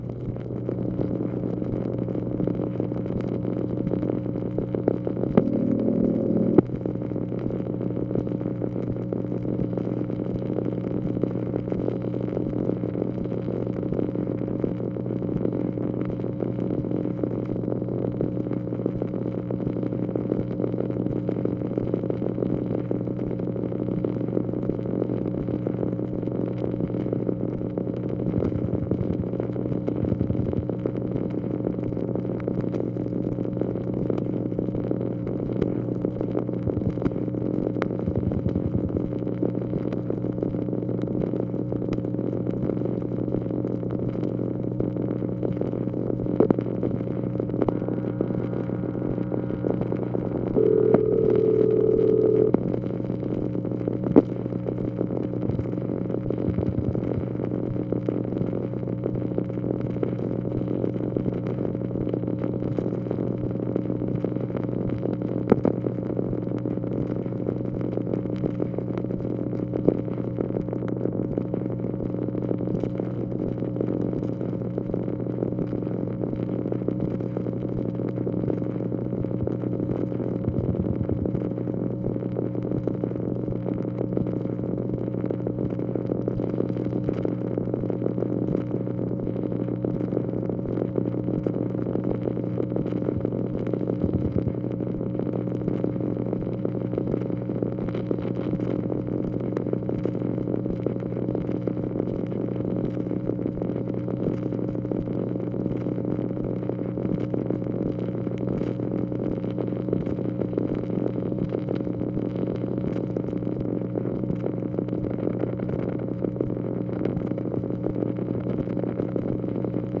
MACHINE NOISE, February 12, 1965
Secret White House Tapes | Lyndon B. Johnson Presidency